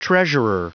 Prononciation du mot treasurer en anglais (fichier audio)
Prononciation du mot : treasurer